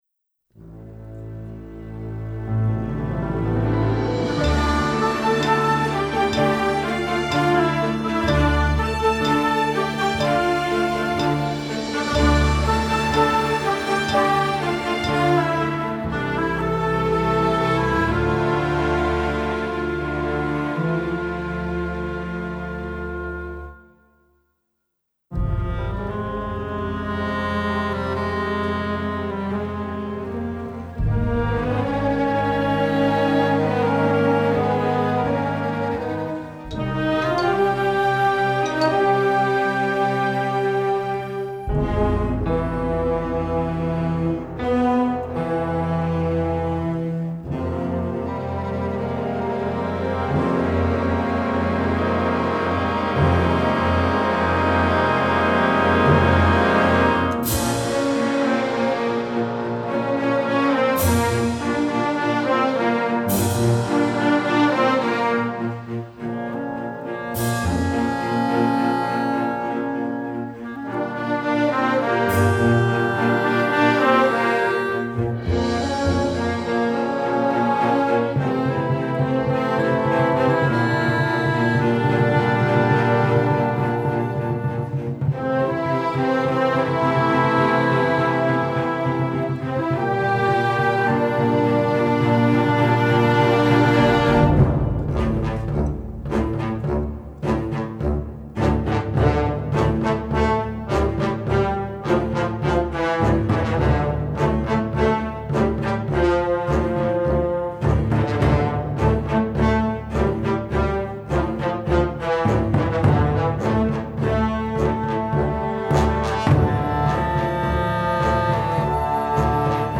Instrumentation: full orchestra